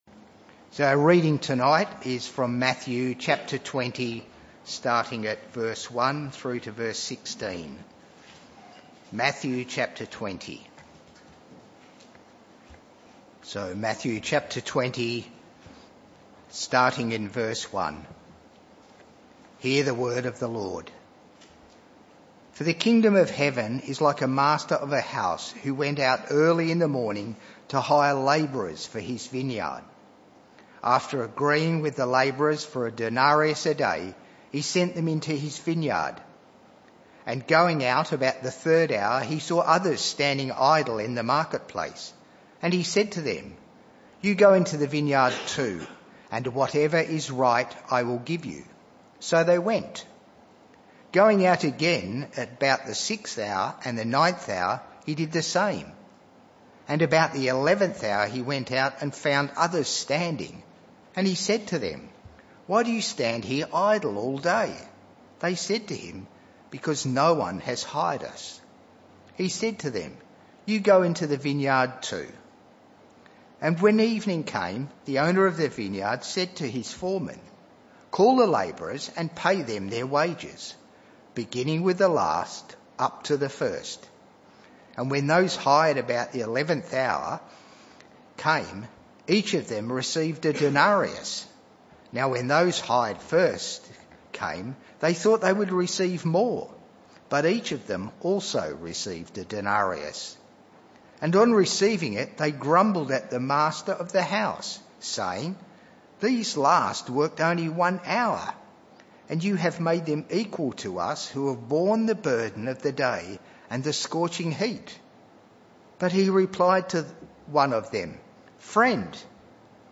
This talk was part of the PM Service series entitled Whispers Of The Kingdom.